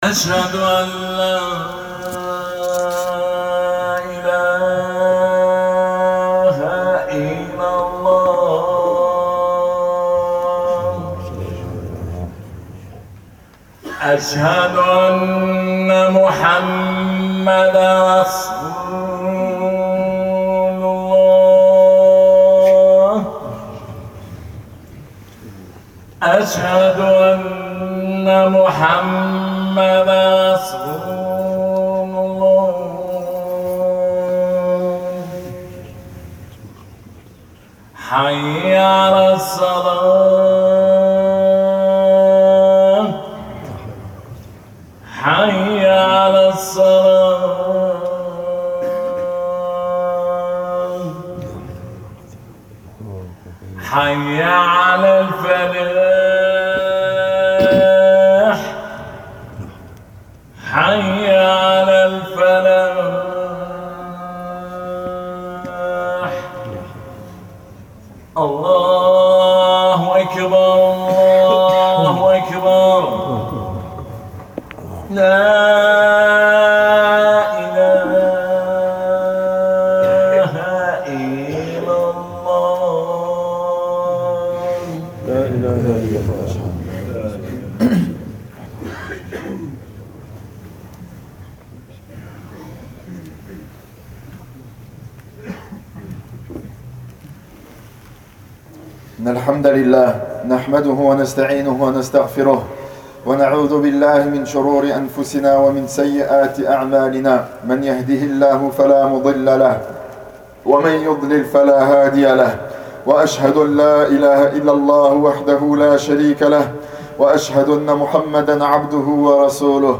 260109_1249 Khutba Révélation du Coran.MP3